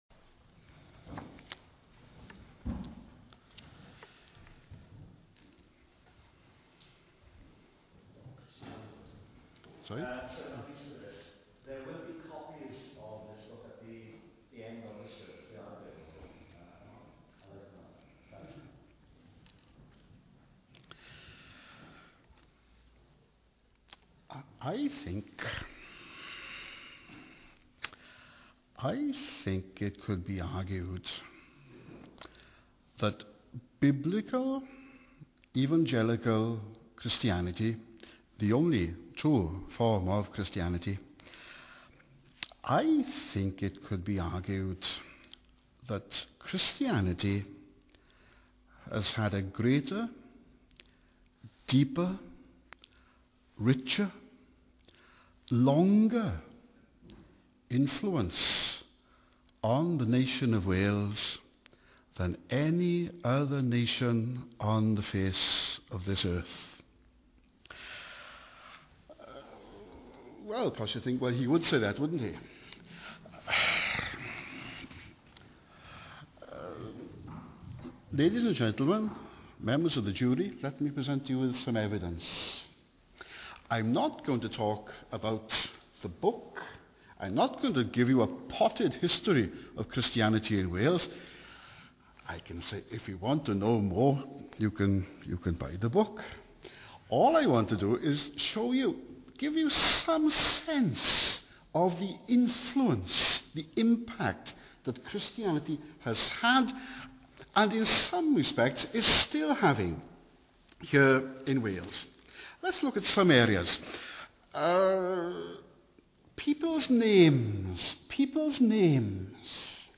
In this sermon, the speaker reflects on the history of Christianity in Wales, acknowledging both the blessings and challenges that have been faced.